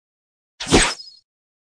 dart.mp3